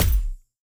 etfx_explosion_sparkle3.wav